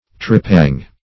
Tripang \Tri*pang"\, n. (Zool.)